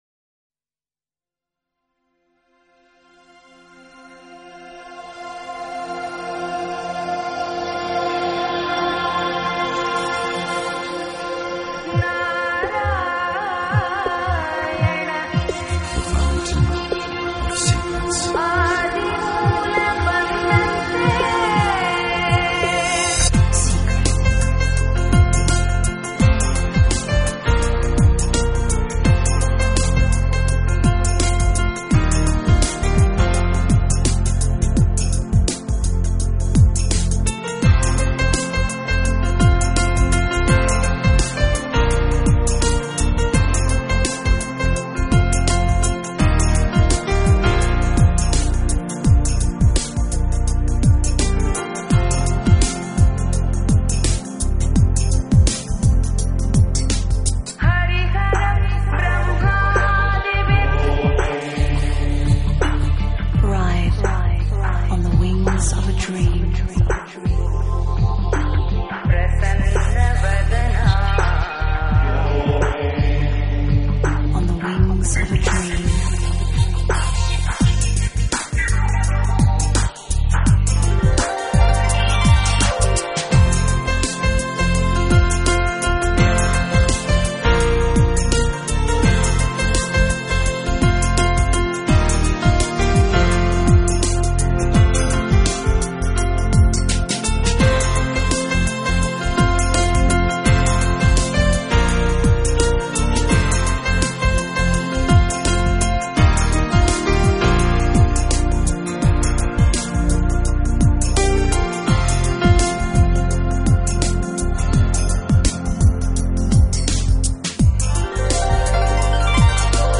专辑语言：纯音乐
这种音乐是私密的，轻柔的，